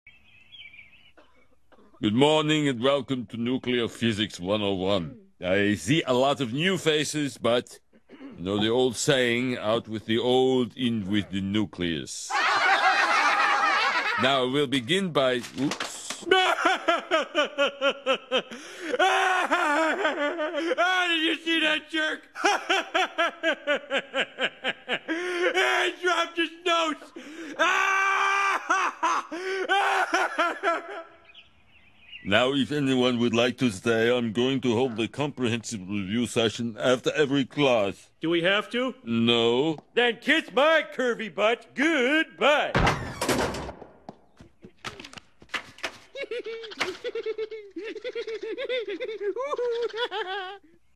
Simpson attends the first physics lecture, laughs to the wrong jokes.